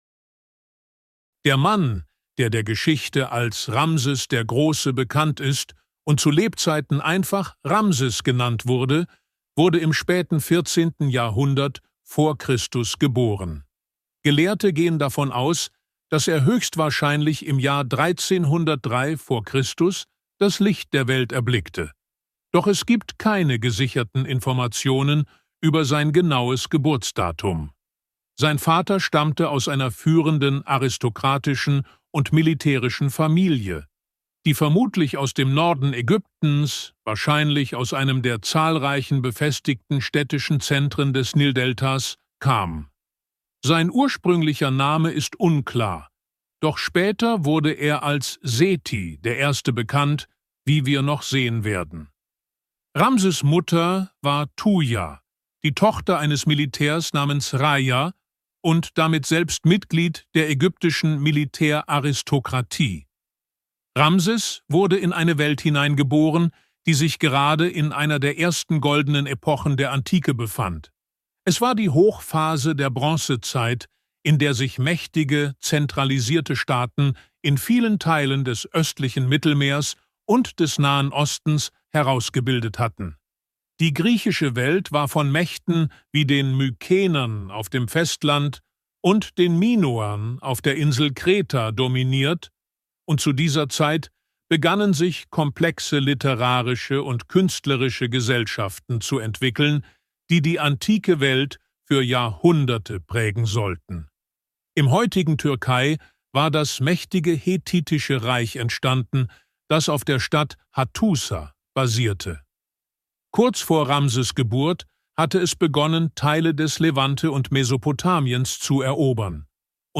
Ramses' Macht-Meditation - Schlafe wie der größte Pharao aller Zeiten (Hörbuch)